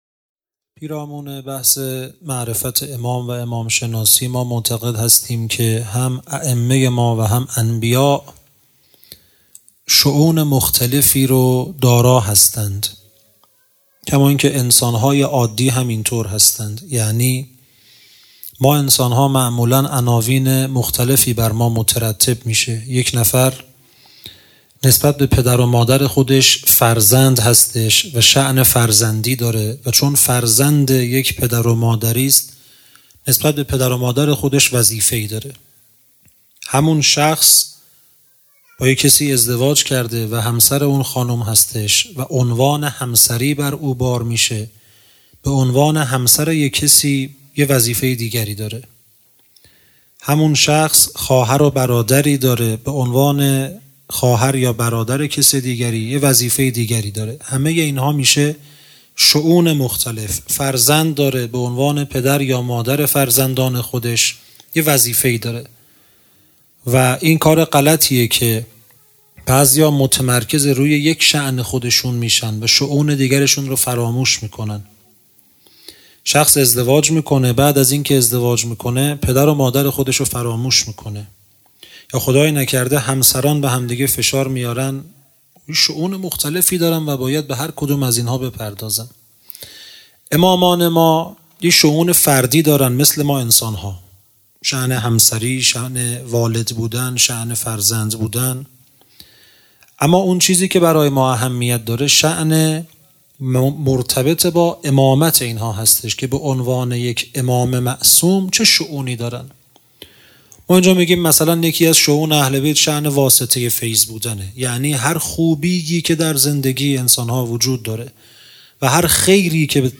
سخنرانی شهادت امام صادق